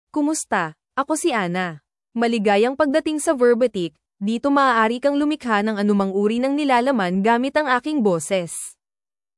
FemaleFilipino (Philippines)
Voice sample
Female
Convert any text to natural Filipino speech using Anna's female voice.